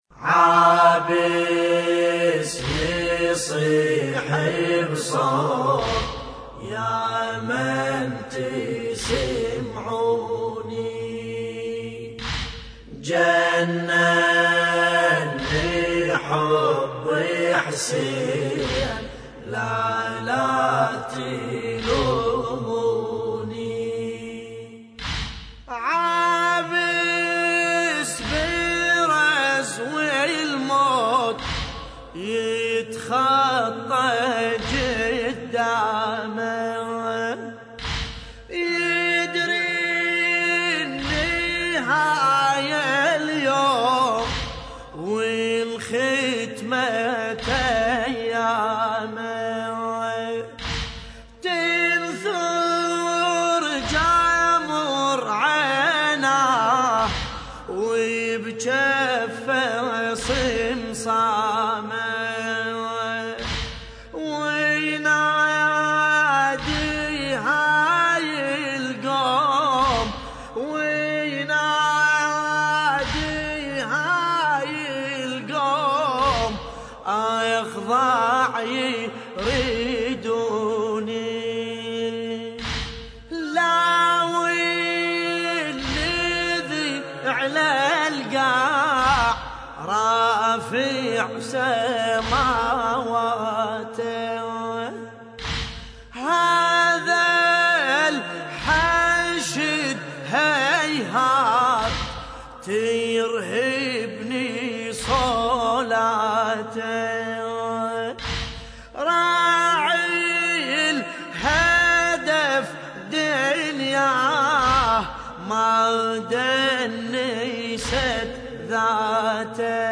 تحميل : عابس يصيح بصوت يا من تسمعوني جنني حب حسين لا لا تلوموني / الرادود باسم الكربلائي / اللطميات الحسينية / موقع يا حسين